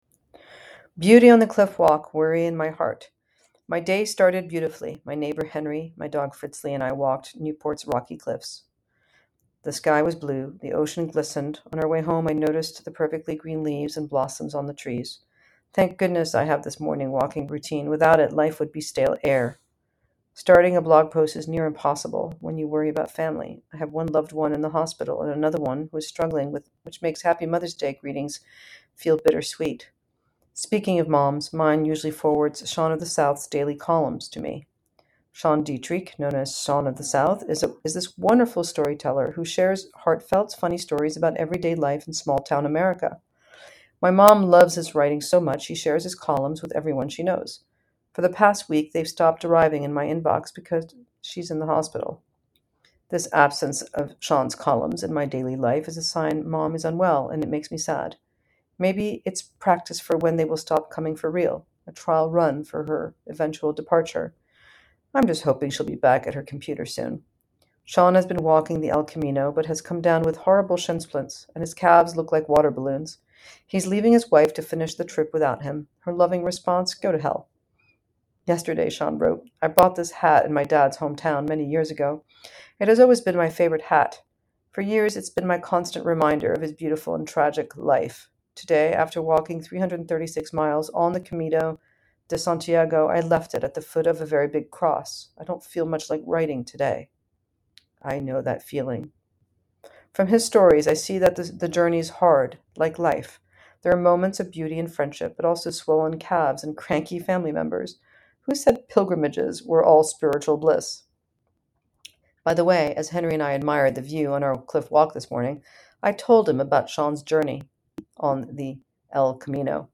A narrated essay from The Pressures of Privilege.